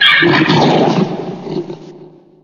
gamedata / sounds / monsters / psysucker / die_1.ogg
die_1.ogg